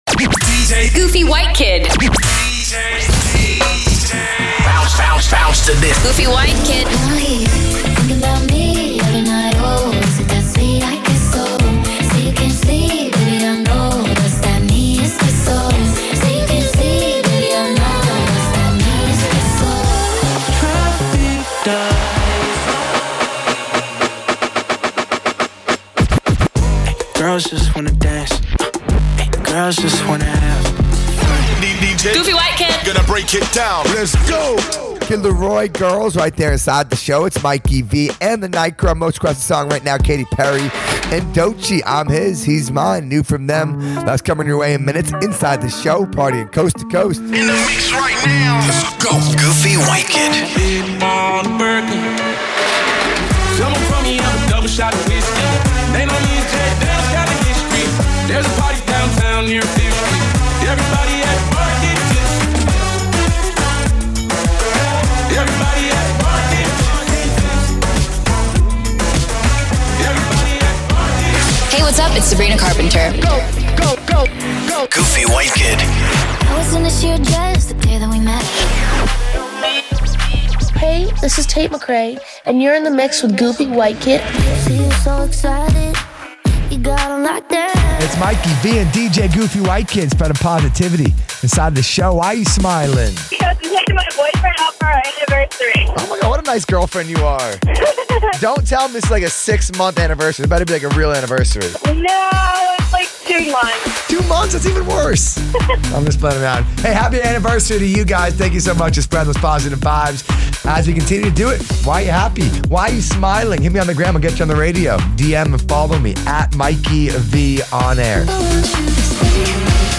CHR & RHYTHMIC FORMATS